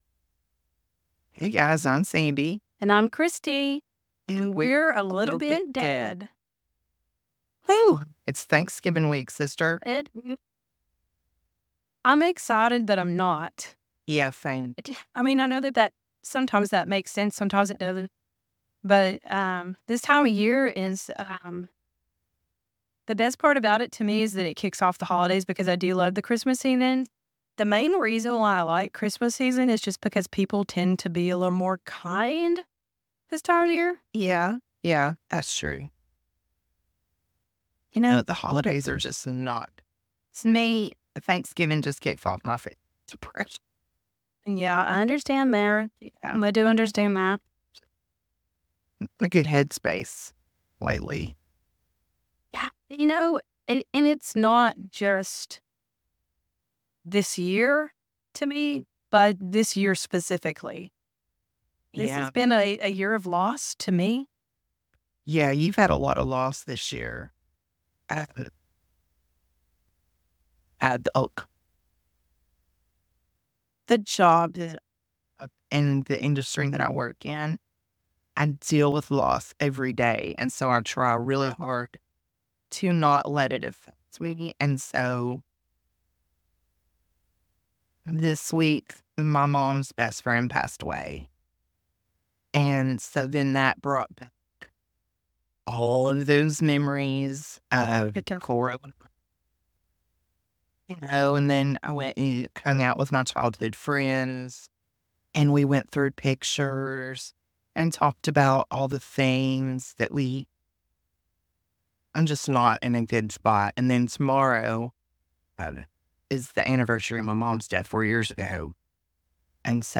We are still working on editing skills sorry there are a few choppy spots :)